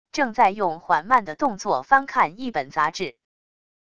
正在用缓慢的动作翻看一本杂志wav音频